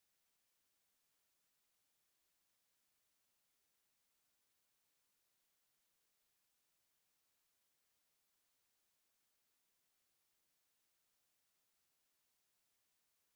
ambience_beach.ogg